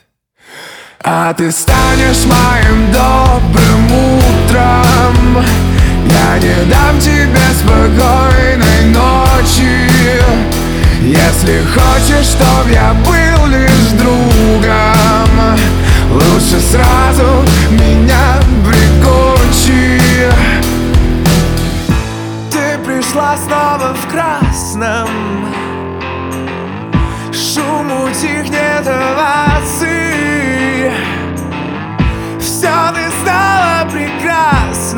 Pop Rock Pop